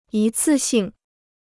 一次性 (yī cì xìng): one-off (offer); one-time.